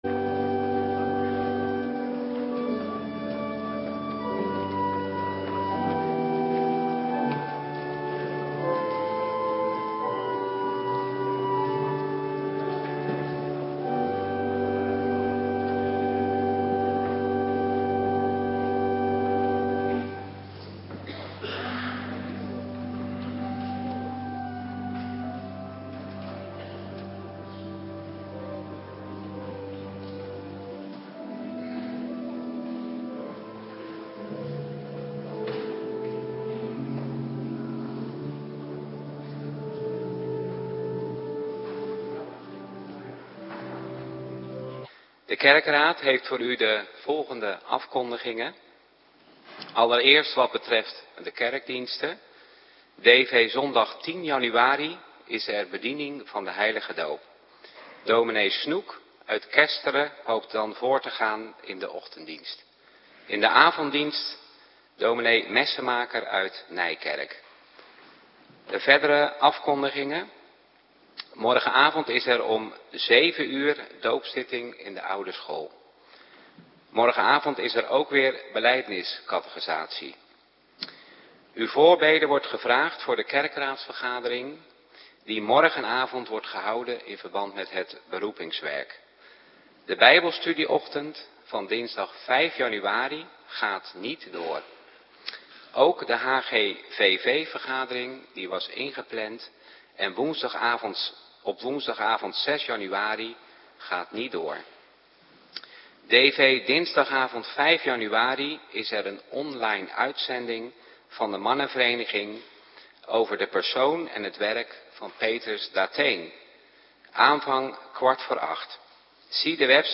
Morgendienst - Cluster 3
Locatie: Hervormde Gemeente Waarder